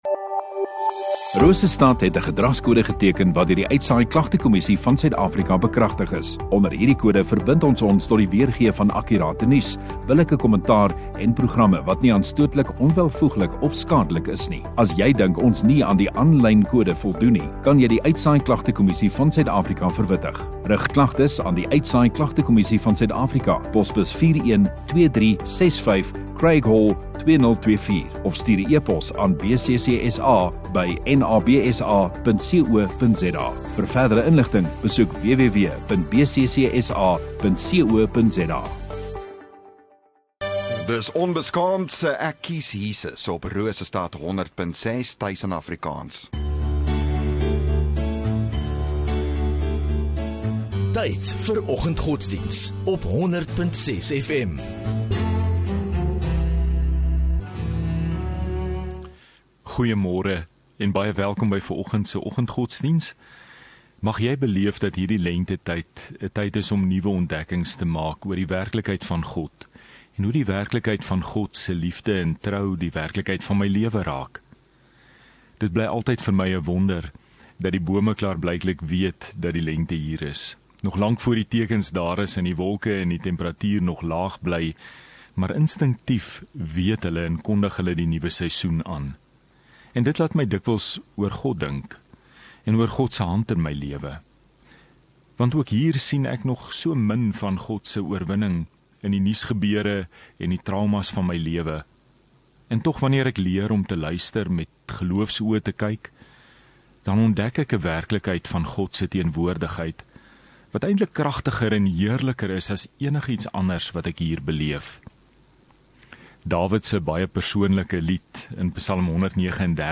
3 Sep Woensdag Oggenddiens